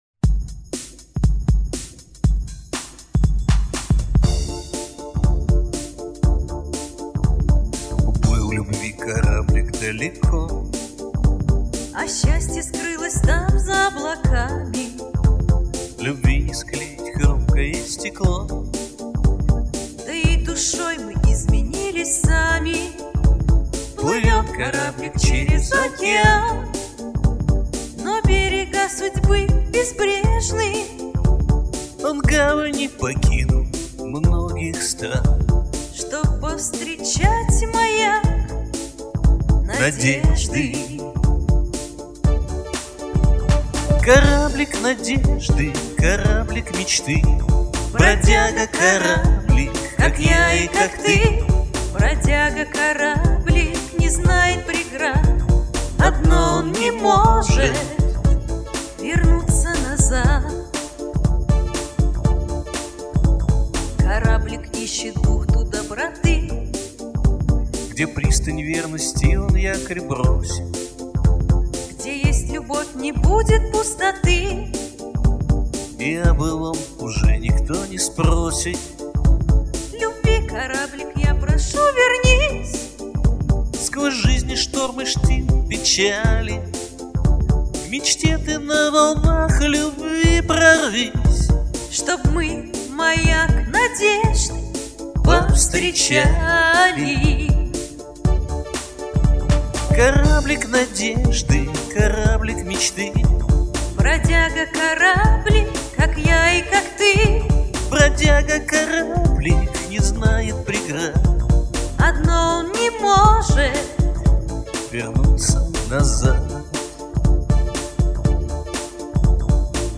Русский поп-шансон